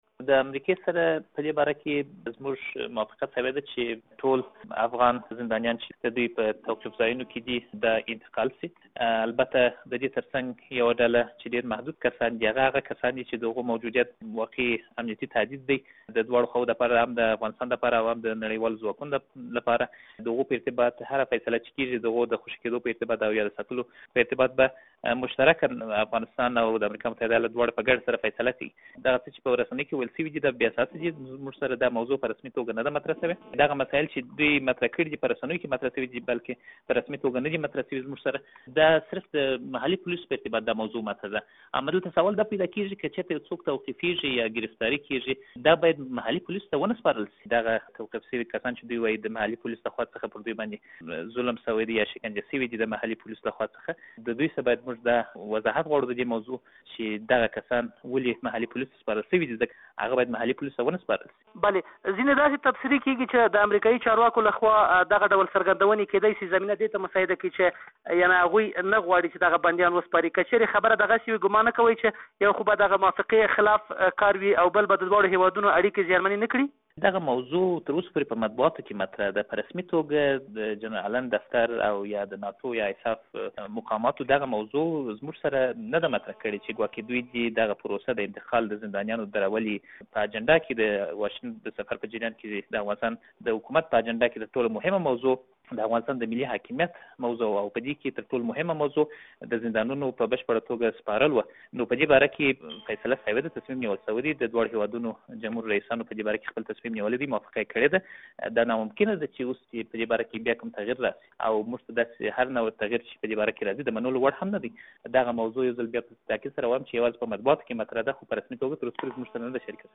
د افغان زندانیانو د نه خوشې کولو په اړه له اېمل فیضي سره مرکه